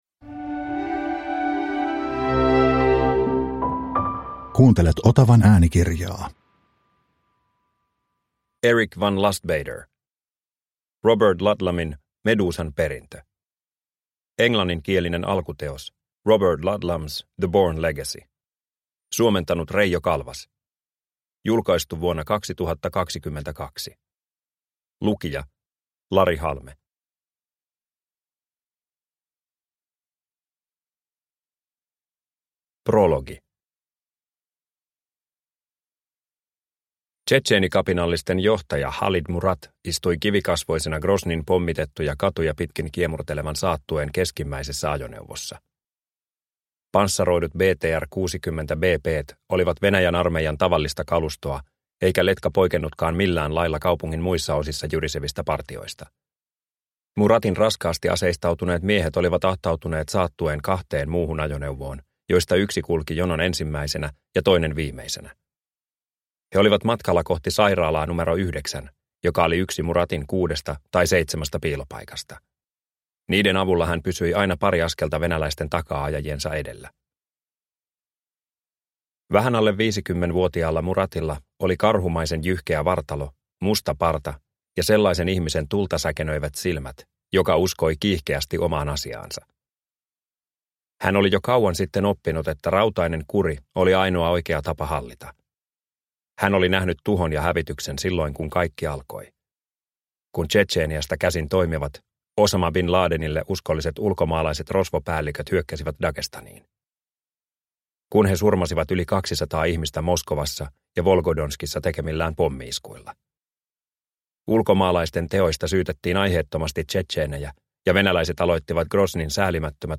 Robert Ludlumin Medusan perintö – Ljudbok – Laddas ner